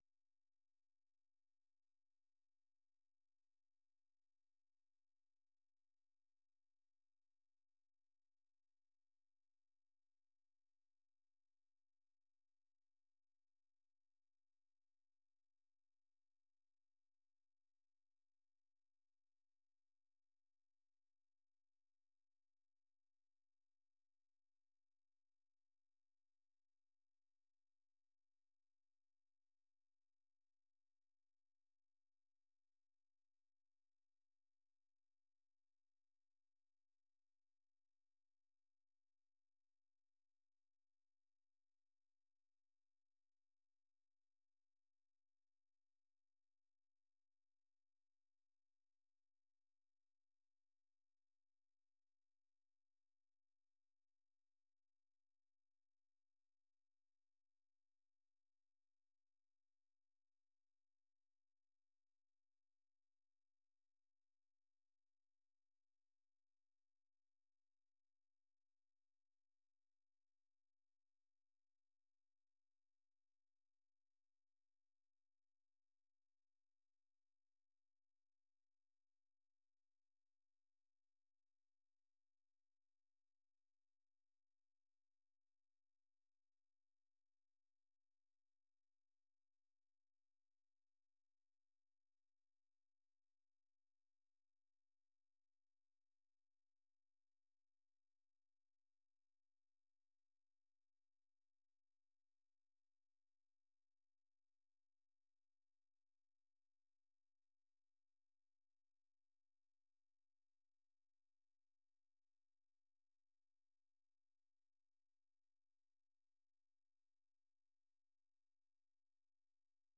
Listen Live - 생방송 듣기 - VOA 한국어